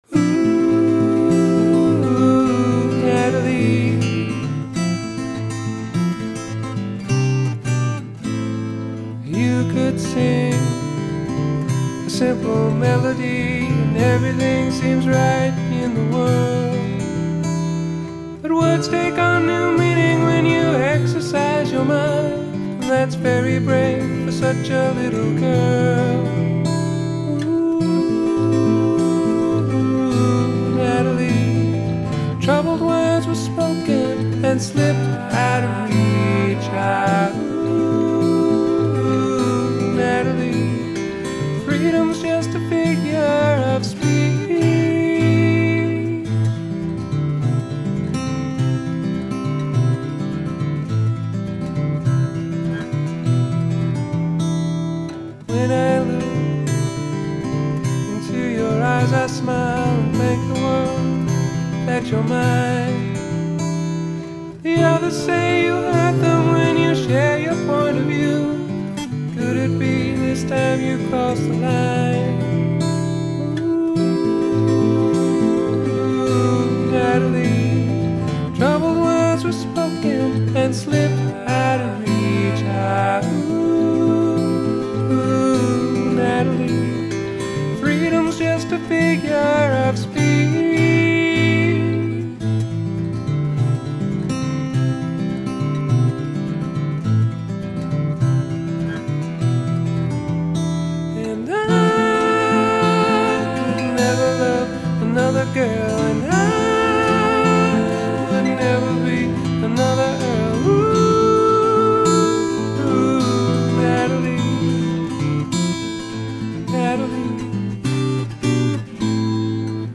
ACOUSTIC DEMOS
Here are some of the rough demo's for the album.